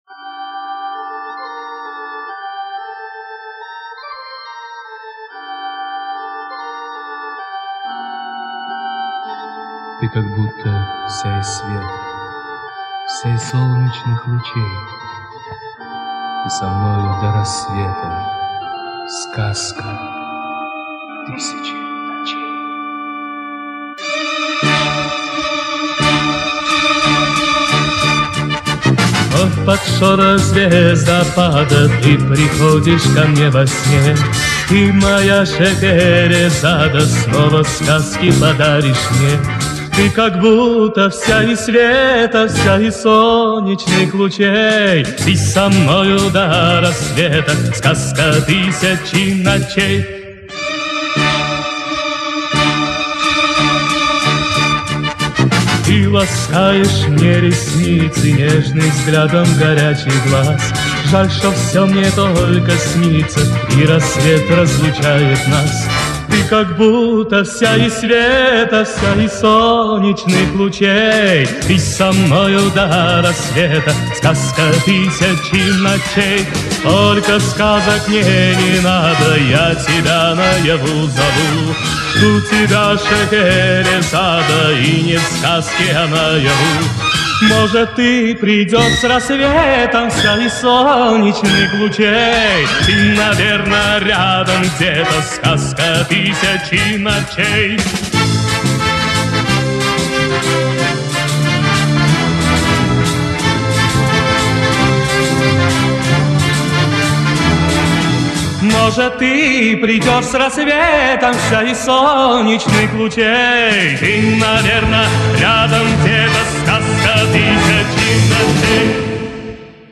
Немножко скорректировал басы.